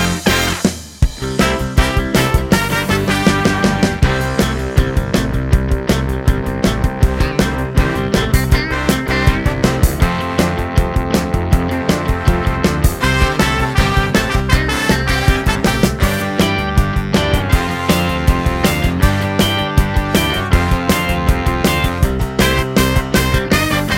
No Backing Vocals Soundtracks 3:13 Buy £1.50